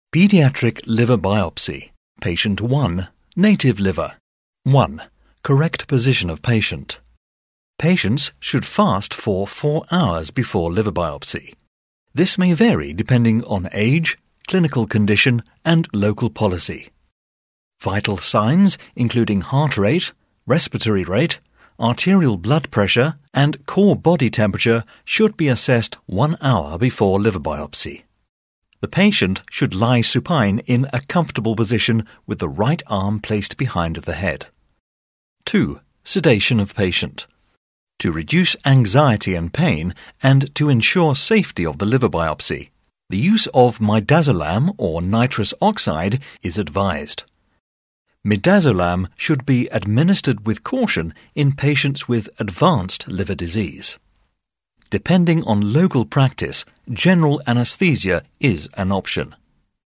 Older Sound (50+)
Warm, articulate British voice with natural authority and clean, confident delivery.
Medical Narrations
0121Medical_vo_demo._Liver_biopsy.mp3